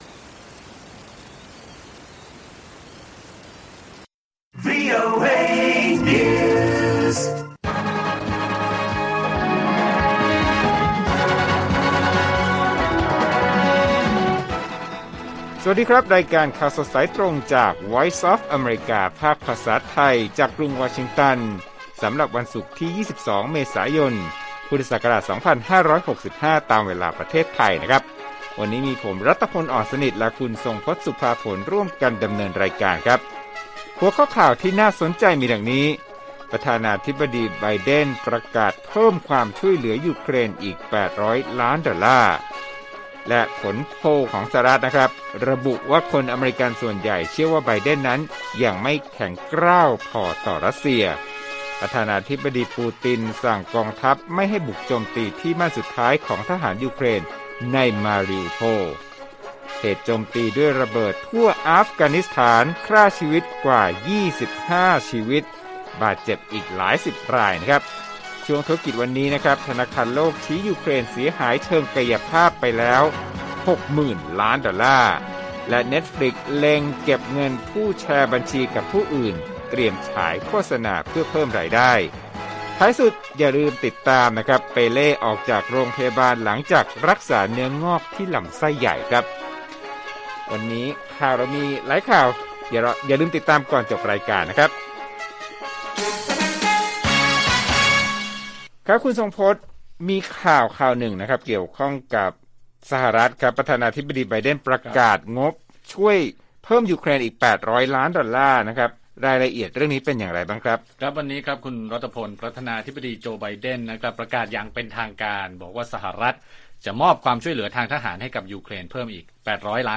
ข่าวสดสายตรงจากวีโอเอไทย วันศุกร์ ที่ 22 เม.ย. 2565